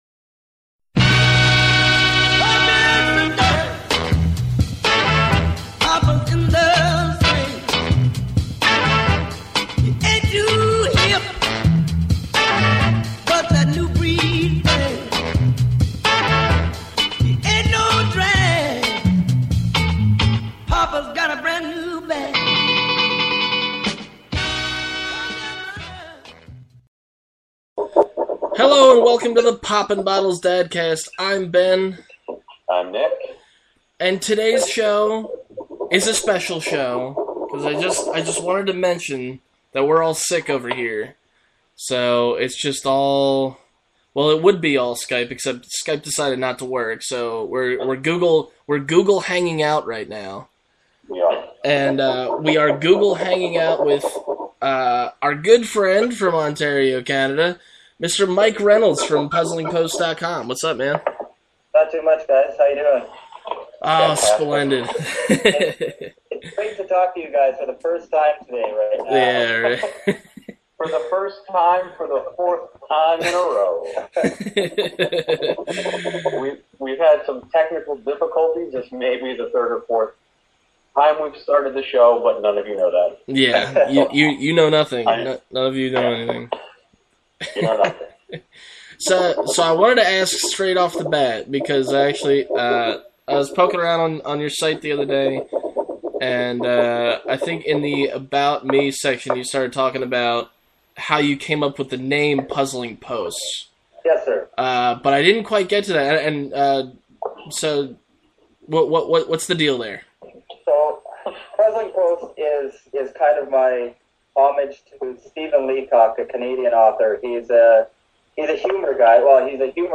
amid technical difficulties!